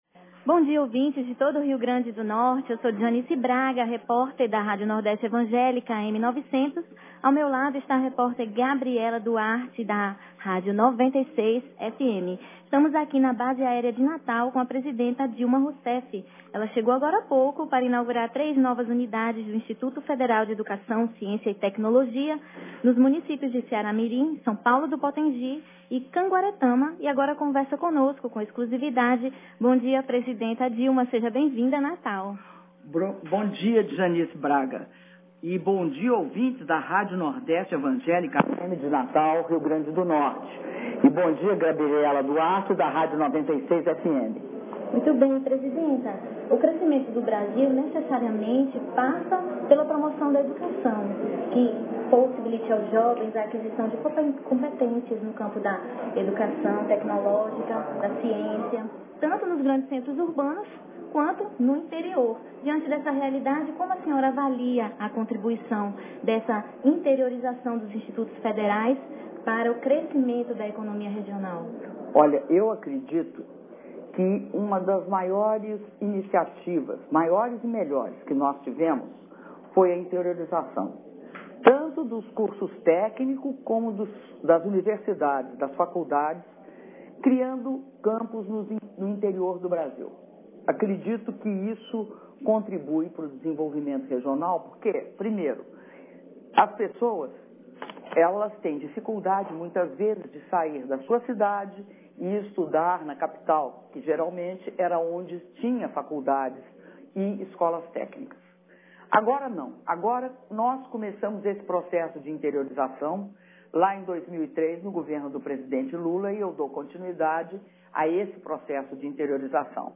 Entrevista concedida pela Presidenta da República, Dilma Rousseff, para emissoras locais de rádio - Natal/RN